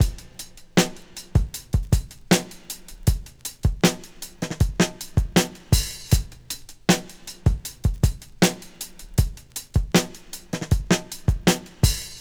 • 79 Bpm Breakbeat F# Key.wav
Free drum beat - kick tuned to the F# note. Loudest frequency: 1962Hz
79-bpm-breakbeat-f-sharp-key-9Bn.wav